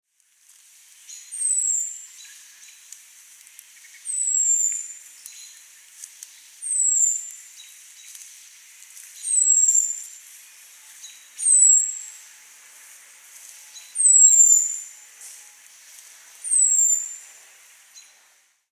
Звуки дрозда
Черный дрозд Turdus merula